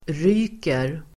Uttal: [r'y:ker]